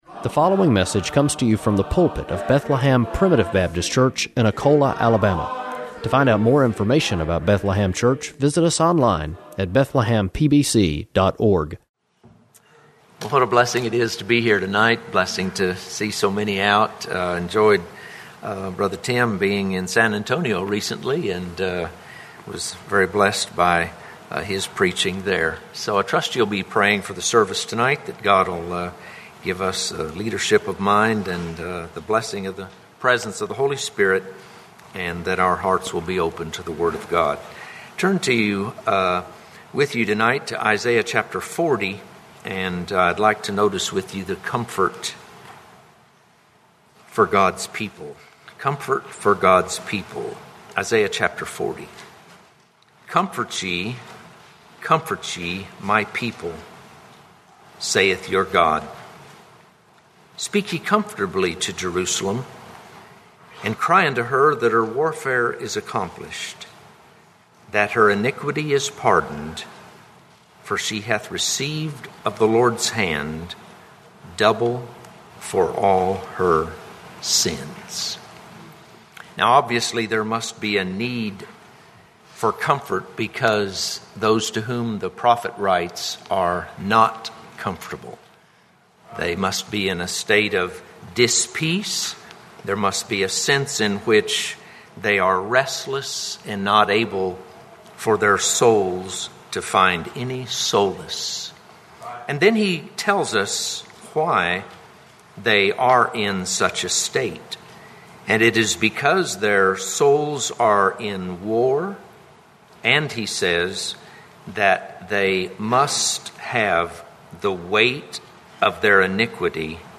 Preached April 19, 2017 http